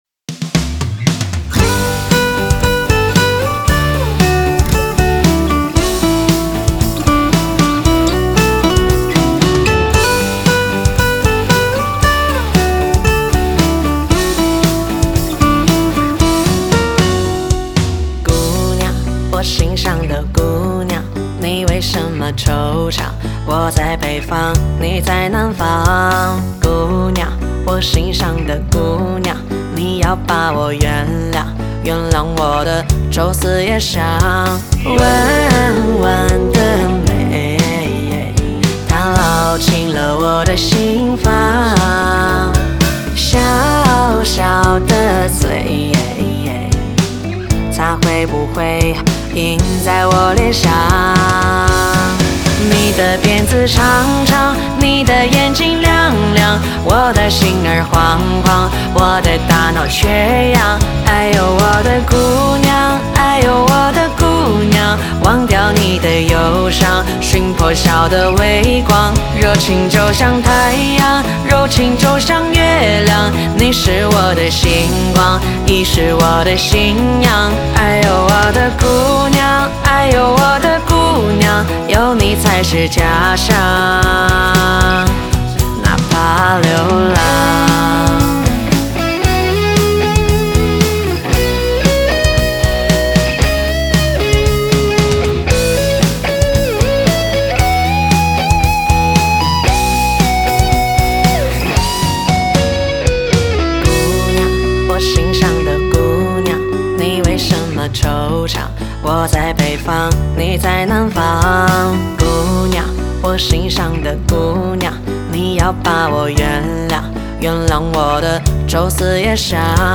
Ps：在线试听为压缩音质节选，体验无损音质请下载完整版
吉他
和声